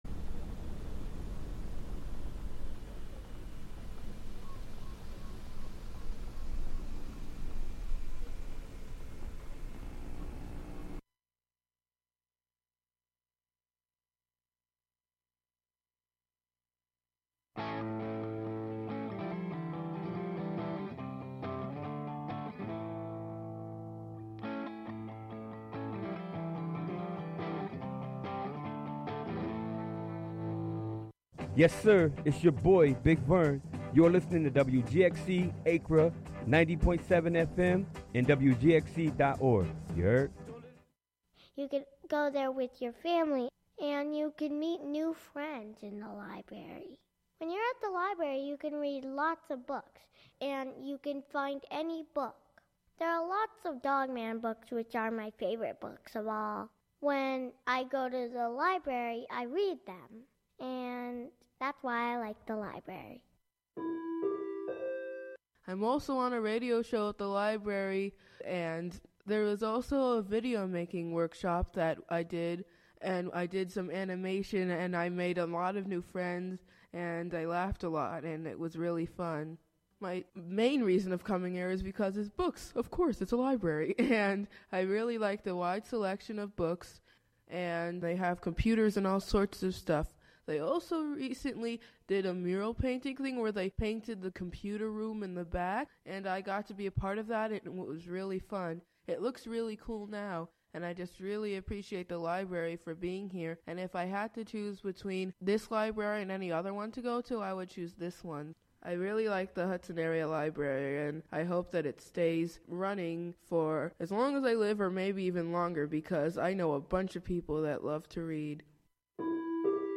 Counting down ten new sounds, stories, or songs, "American Top 40"-style. Usually the top ten is recent songs, but sometimes there are thematic countdowns, or local music-themed shows.